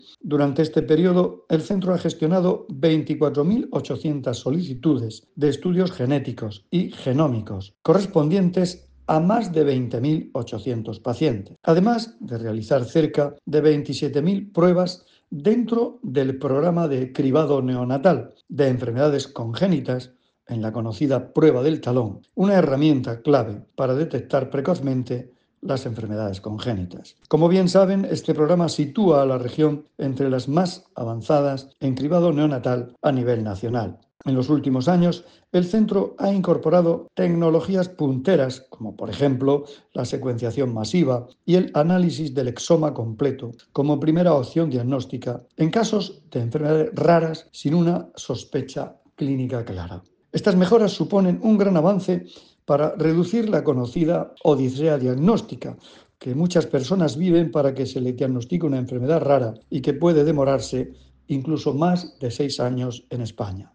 Sonido/ Declaraciones del consejero de Salud, Juan José Pedreño, sobre la actividad del Centro de Bioquímica de 2023 y 2024.